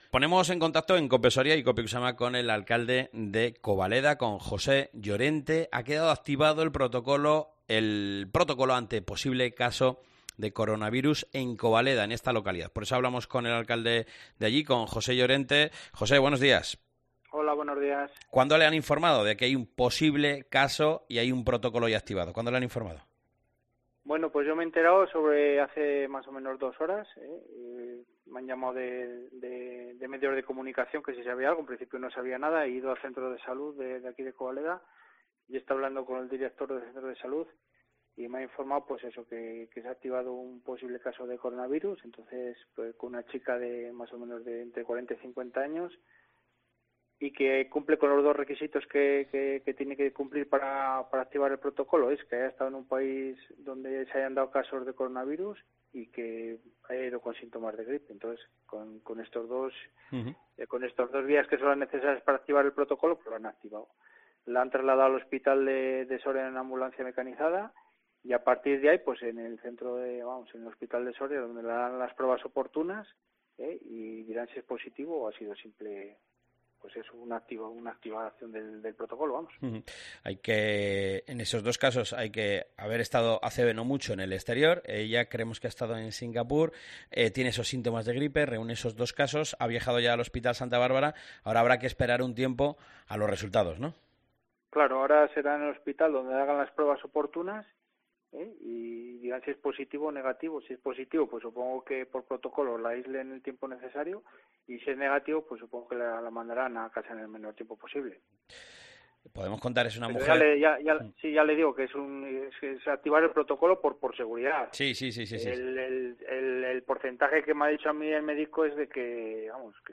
ENTREVISTA Jose Llorente Alcalde de Covaleda